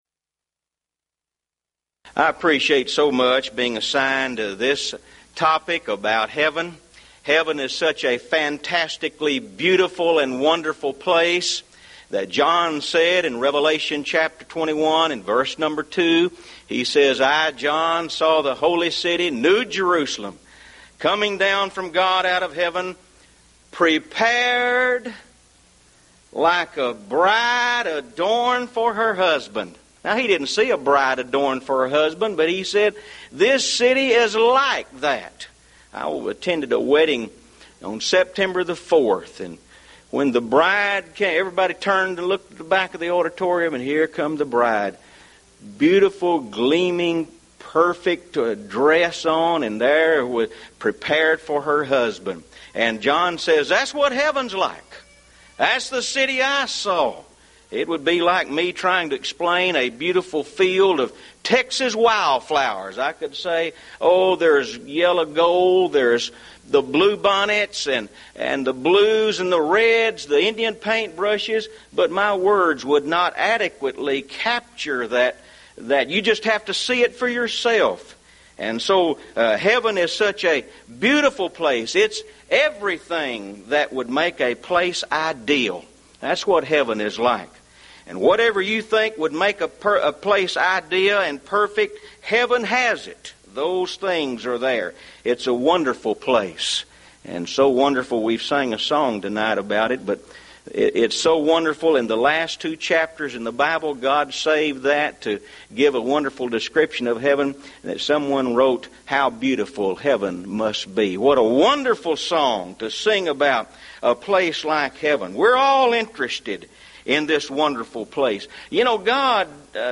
Mid-West Lectures